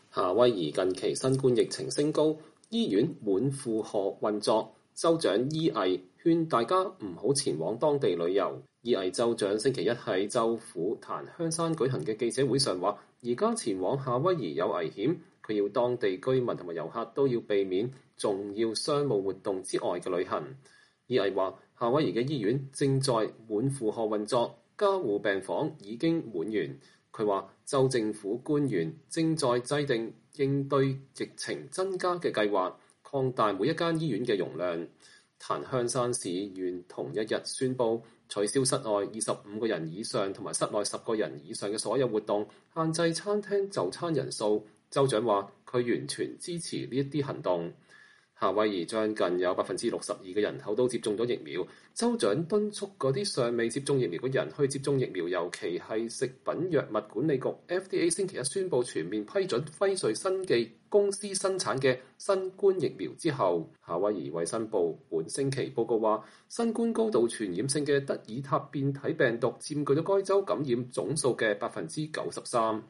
伊藝州長星期一在州府檀香山舉行的記者會上說，現在前往夏威夷有危險。